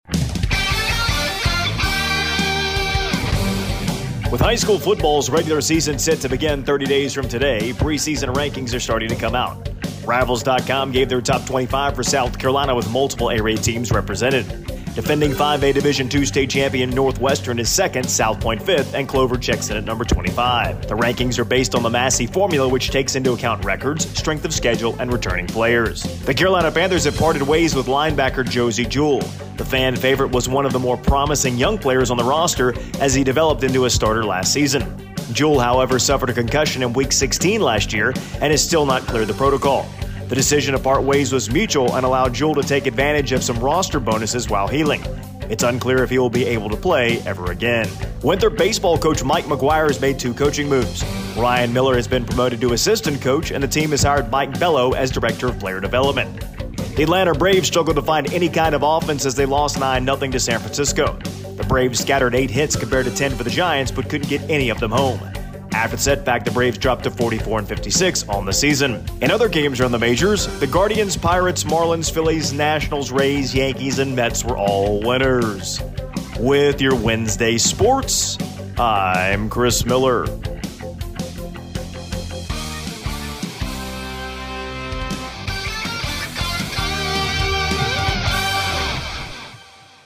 Wednesday Morning Sports Report